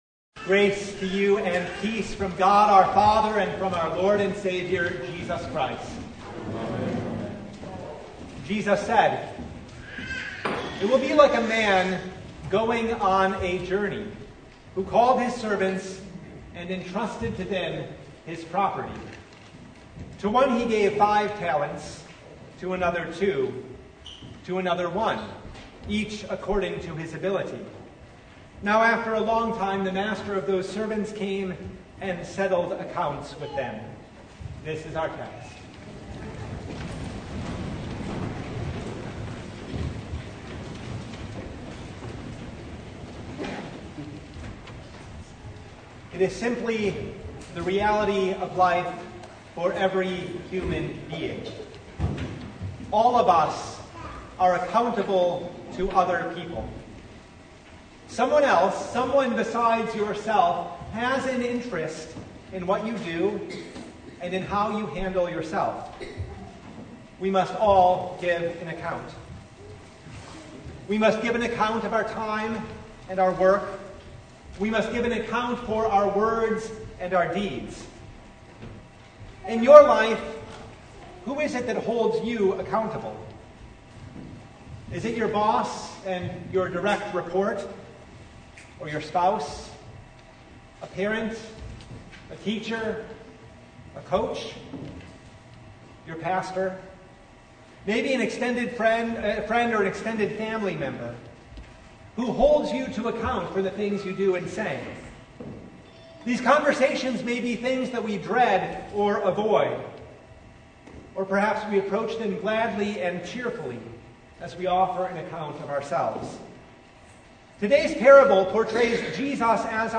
Matthew 24:14-30 Service Type: Sunday Our Lord is not a hard master that we should fear Him.